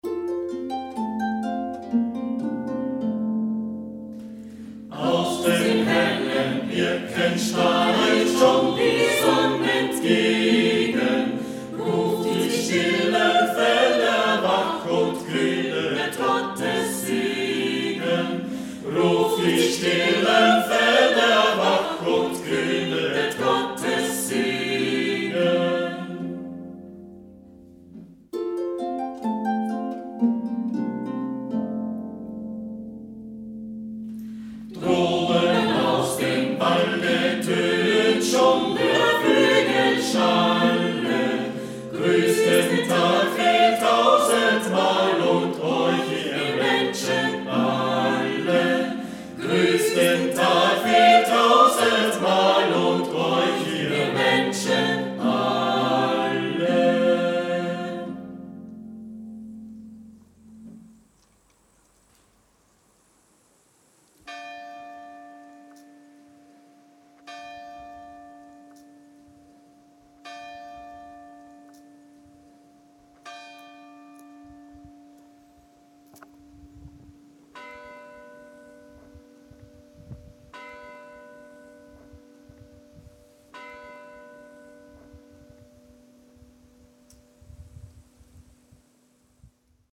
für kleine Singgruppe und Harfenbegleitung gesetzt.
Sängerinnen und Sängern aus unserer Region.
Harfe